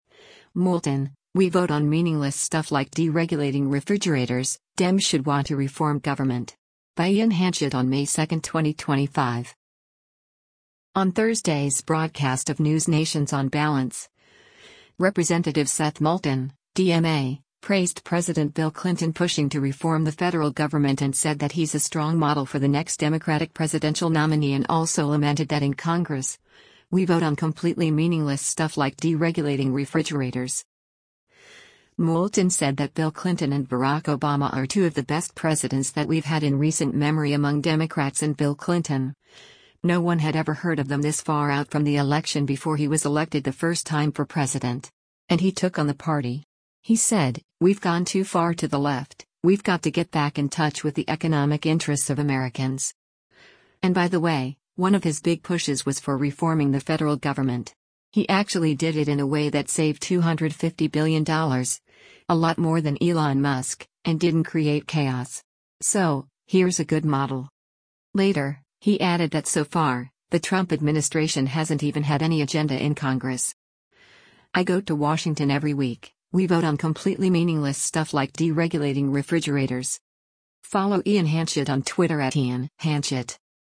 On Thursday’s broadcast of NewsNation’s “On Balance,” Rep. Seth Moulton (D-MA) praised President Bill Clinton pushing to reform the federal government and said that he’s a strong model for the next Democratic presidential nominee and also lamented that in Congress, “we vote on completely meaningless stuff like deregulating refrigerators.”